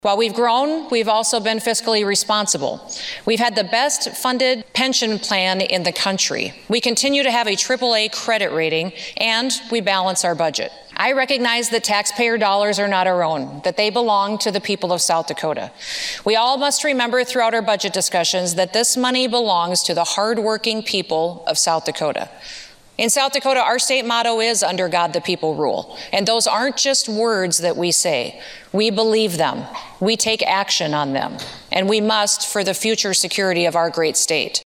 South Dakota Governor Kristi Noem delivered her 2022 Budget Address today (Dec. 6, 2022), outlining her spending plan for part of Fiscal Year 2023 and all of Fiscal Year 2024.